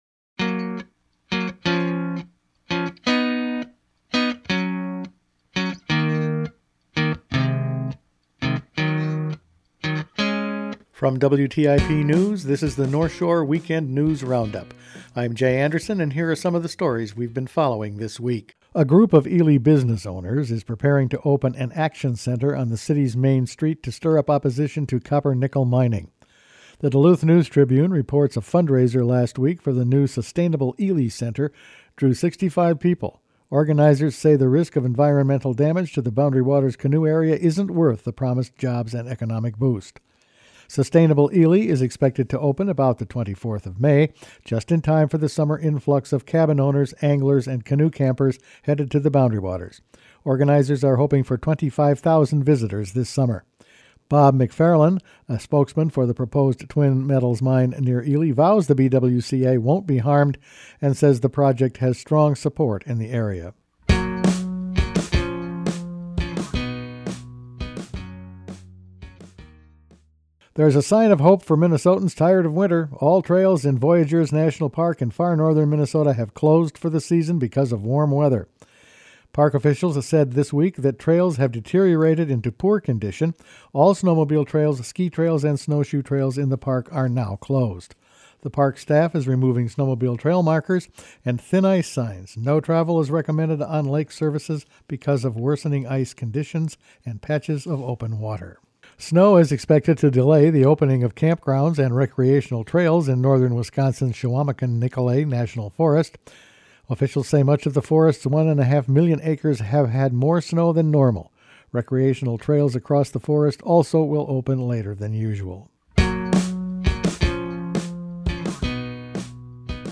Each weekend WTIP news produces a round up of the news stories they’ve been following this week. Ely business owners organize against non-ferrous mining.